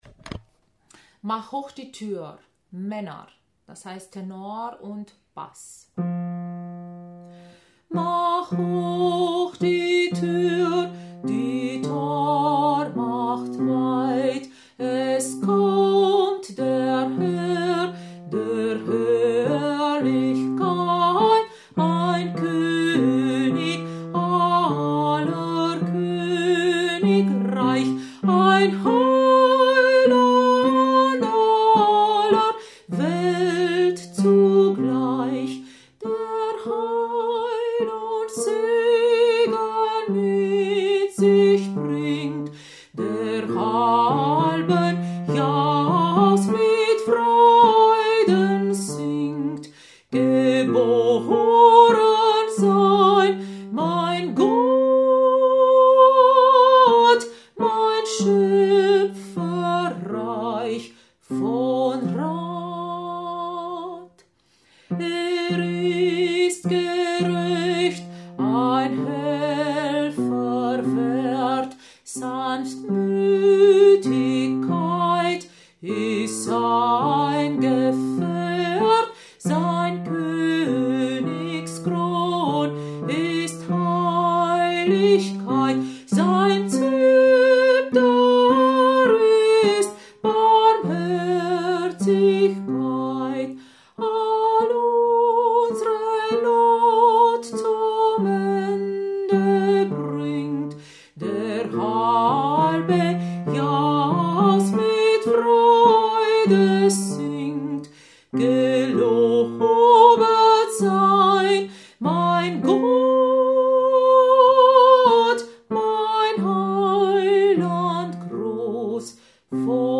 Macht hoch die Tür – Männer
Macht-hoch-die-Tür-Männer.mp3